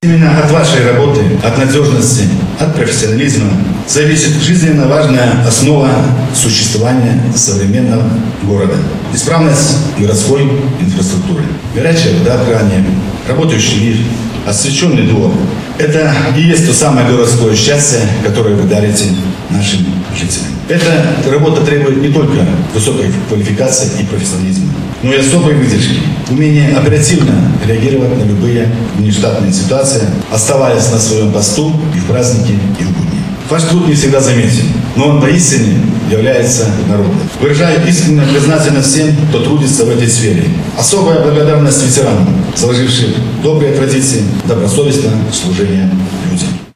Торжественное мероприятие развернулось в стенах Дворца детского творчества.
Они дарят нам комфорт и уют, подчеркнул в своем поздравлении глава Барановичского горсовета депутатов Виктор Колосовский.